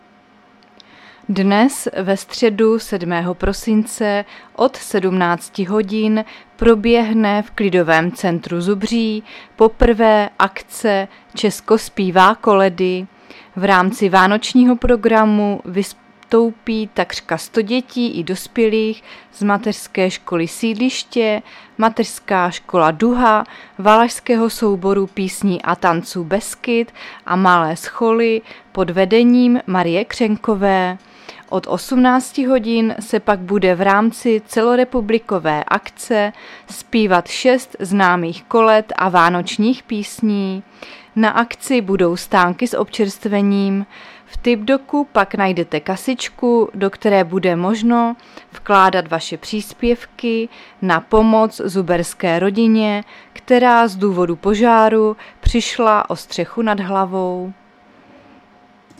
Záznam hlášení místního rozhlasu 7.12.2022